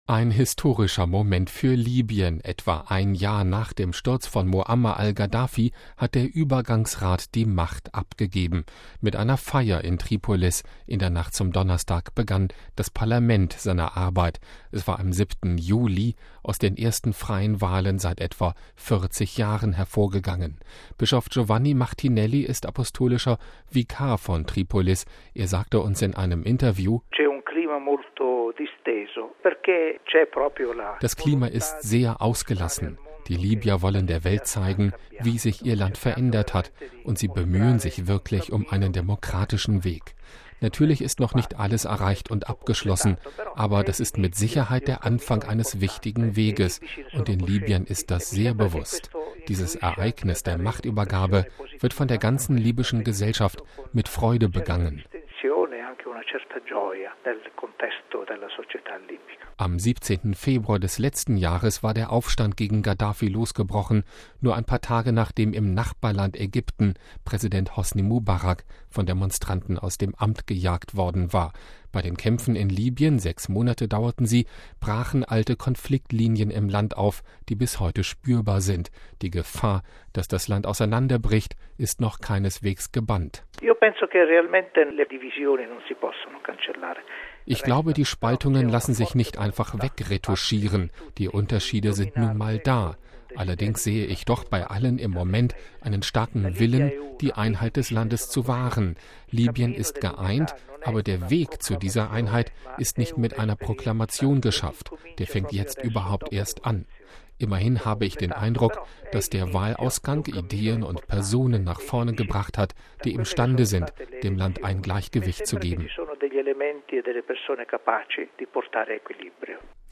Bischof Giovanni Martinelli ist Apostolischer Vikar von Tripolis – er sagte uns in einem Interview: